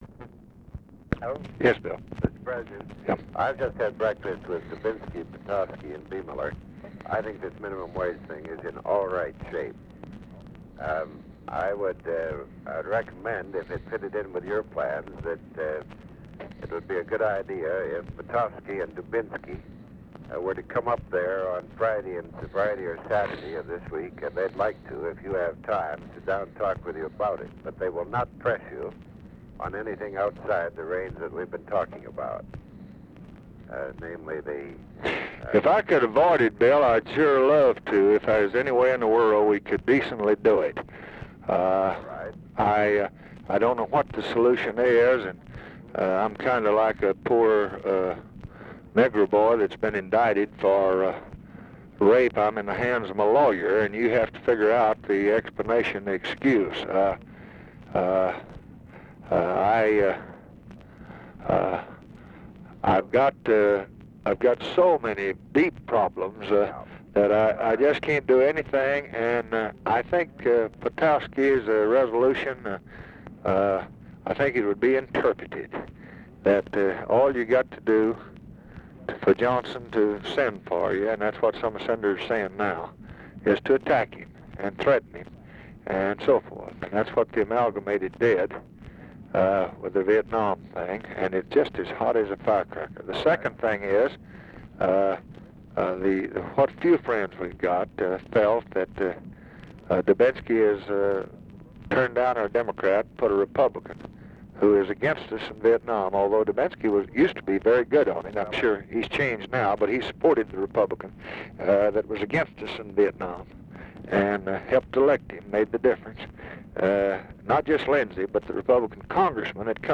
Conversation with WILLARD WIRTZ, February 22, 1966
Secret White House Tapes